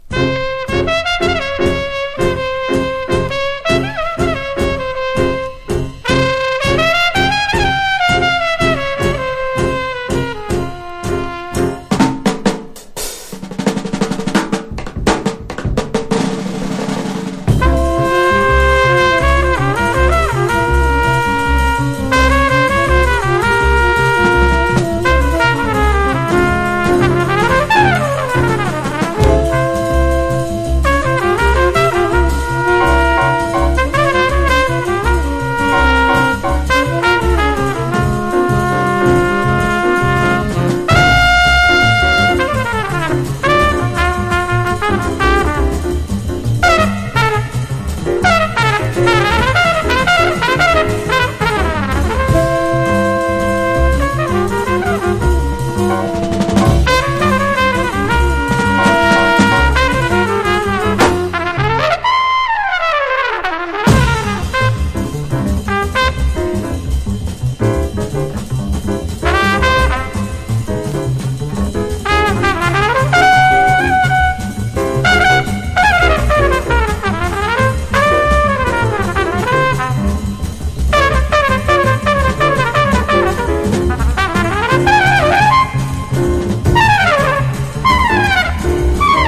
ハードバップ